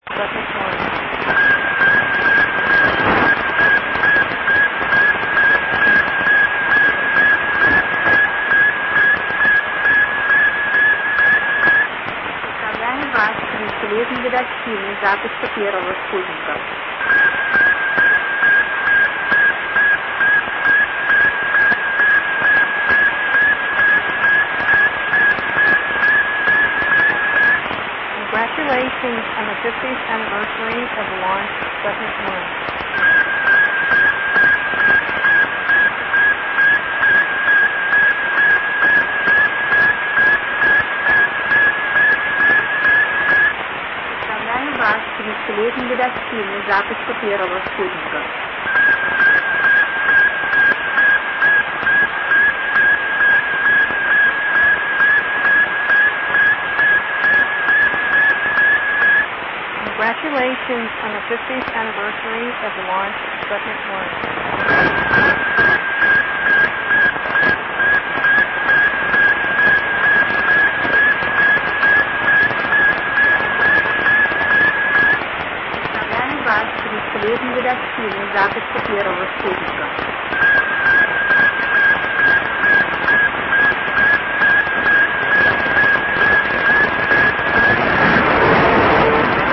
Telemetria e Messaggio
sputnikbeacon.mp3